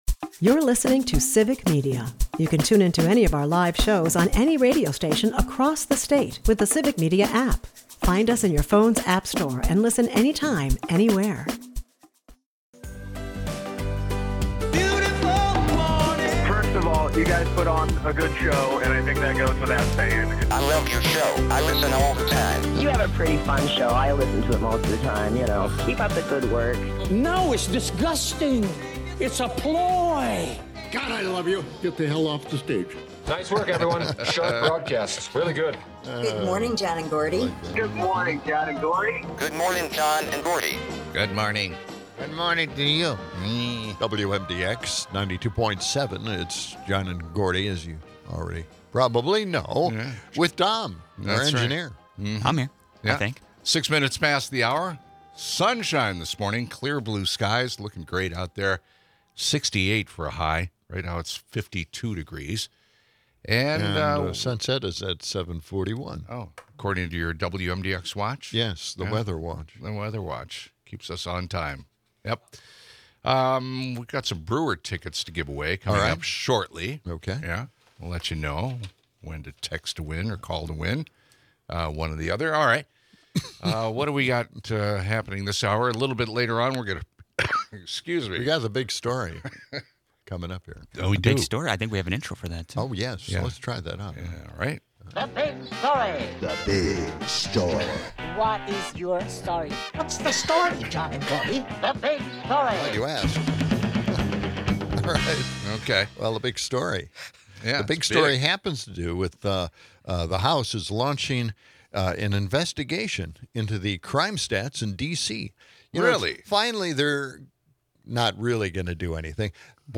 The episode kicks off with upbeat banter about the weather and giveaway of Brewers tickets.